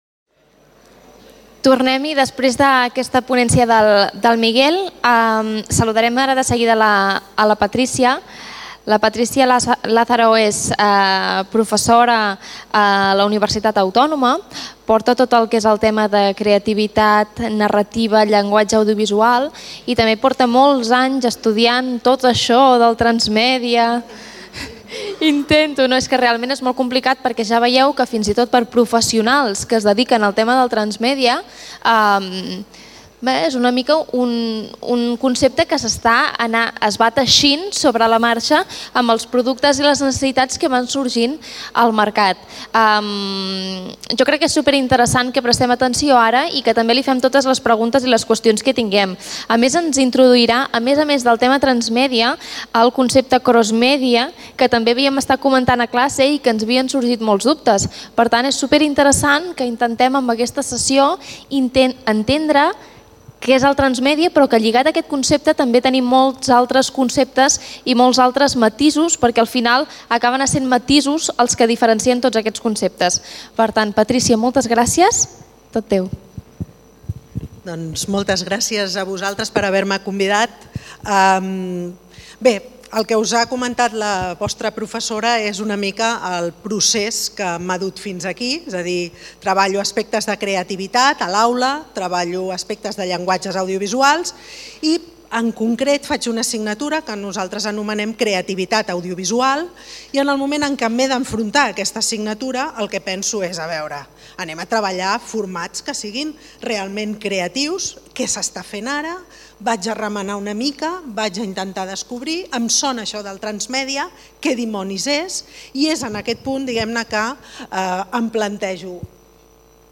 Ponència